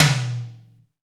Index of /90_sSampleCDs/Roland L-CD701/TOM_Rock Toms 1/TOM_Rock Toms 1
TOM ROTOTO1D.wav